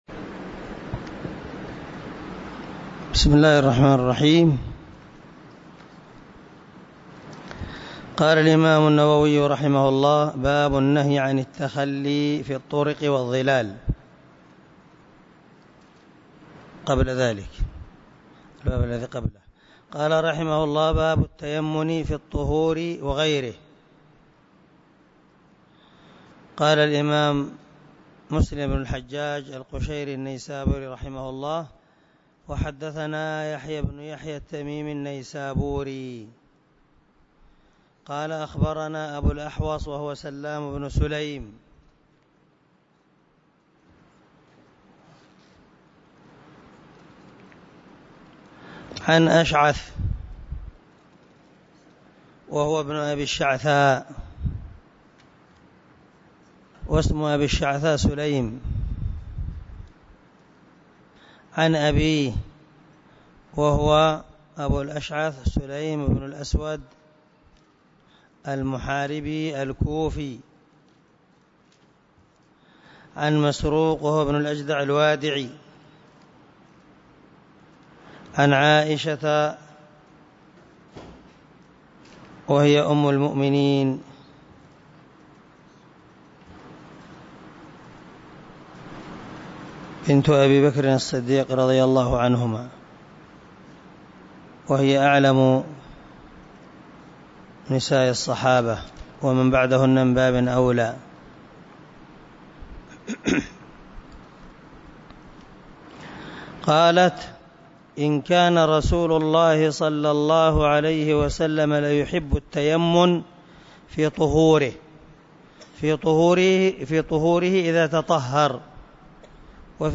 201الدرس 29 من شرح كتاب الطهارة حديث رقم ( 268 ) من صحيح مسلم
دار الحديث- المَحاوِلة- الصبيحة.